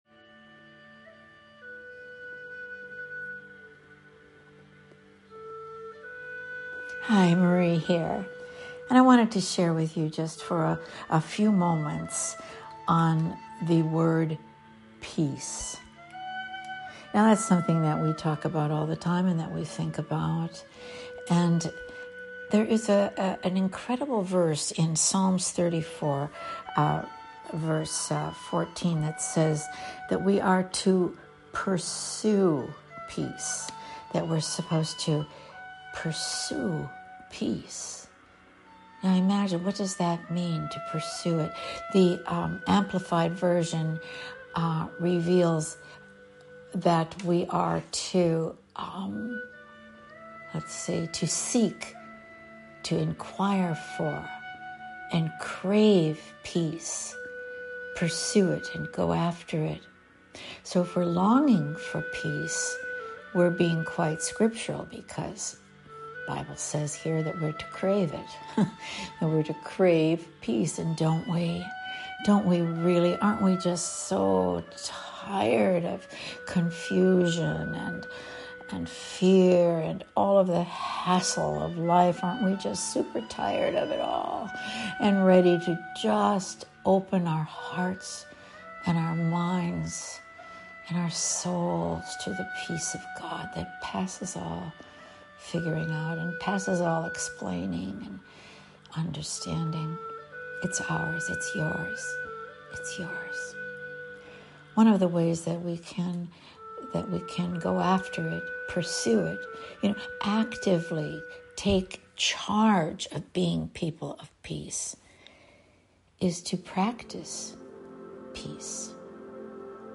a powerful concise meditation under 3 minutes.